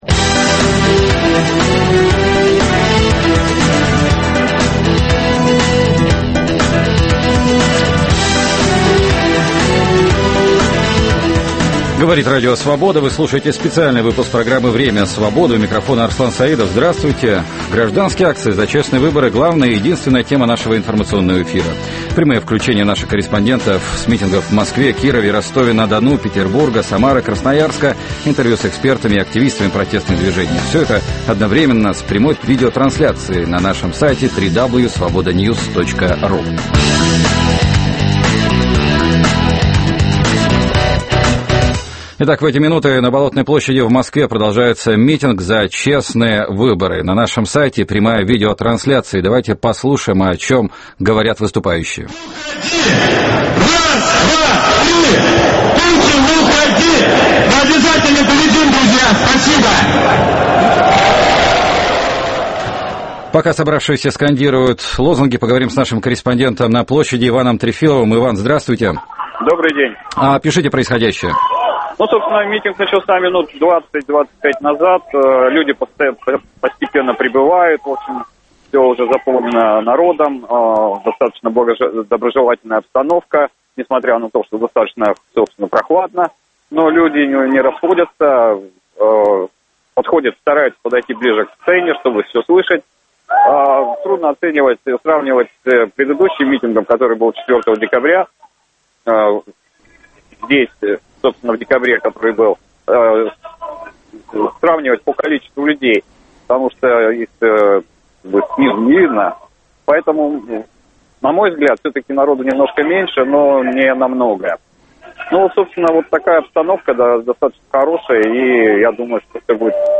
Прямой эфир с Болотной площади в Москве